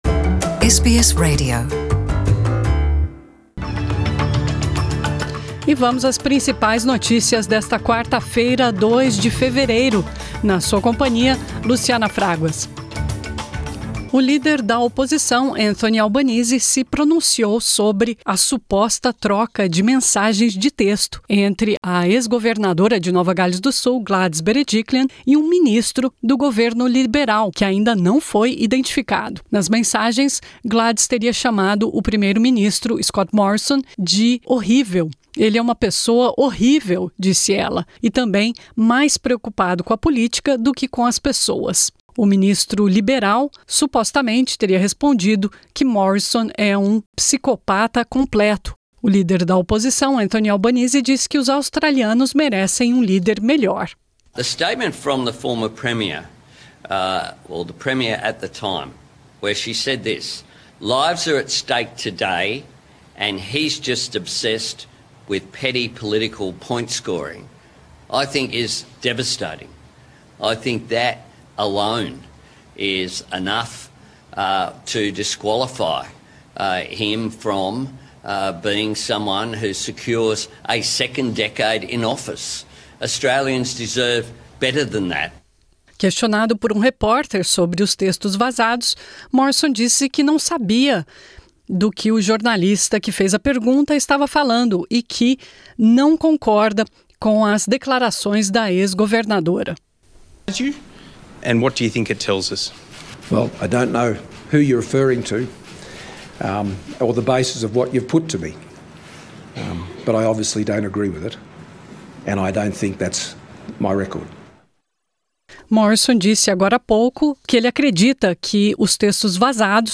Uma troca de mensagens de texto entre a ex-governadora de Nova Gales do Sul, Gladys Berejiklian, e um ministro do governo liberal que ainda não foi identificado, revela ataques da ex-governadora ao primeiro-ministro Scott Morrison. São as notícias da Austrália e do Mundo da Rádio SBS para esta quarta-feira 2 de fevereiro.